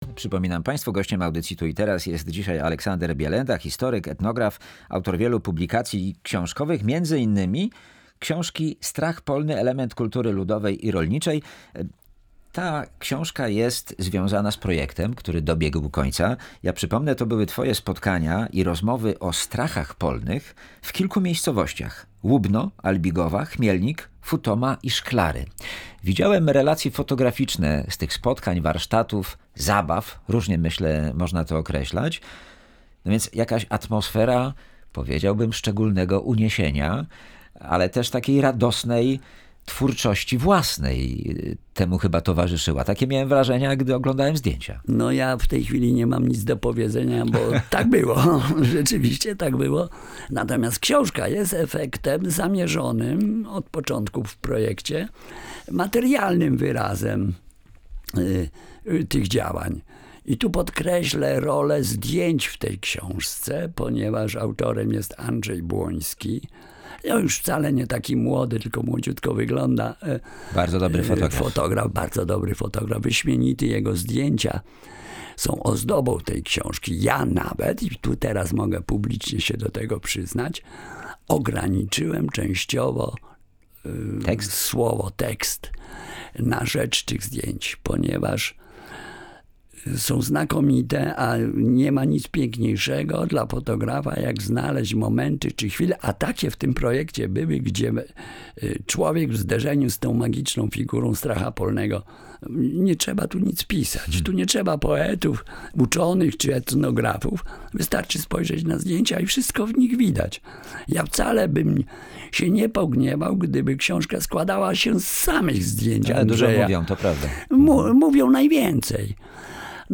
Szczegóły w rozmowie